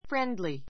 friendly 小 fréndli ふ レ ン ド り 形容詞 比較級 friendlier fréndliə r ふ レ ン ド りア 最上級 friendliest fréndliist ふ レ ン ド りエ スト 友好的な , 好意的な , 親切な, 人なつっこい a friendly nation a friendly nation 友好国 She is friendly to [toward] everybody.